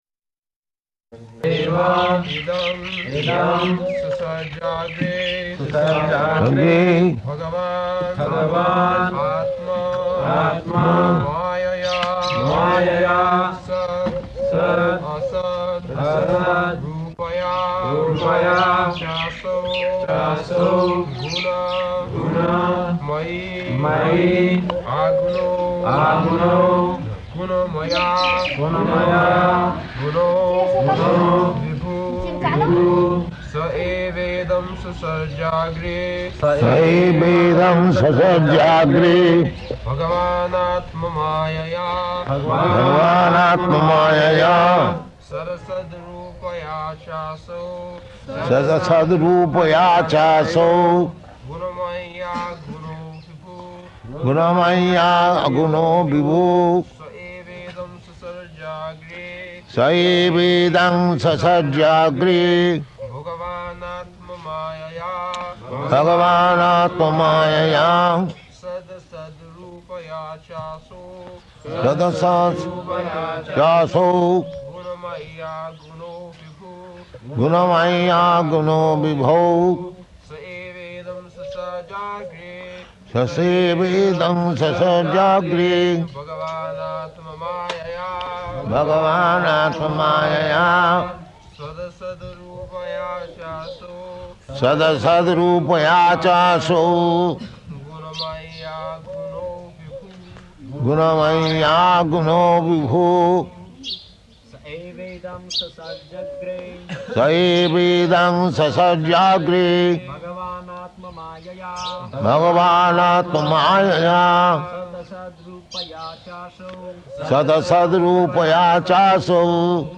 November 9th 1972 Location: Vṛndāvana Audio file
[Prabhupāda and devotees repeat]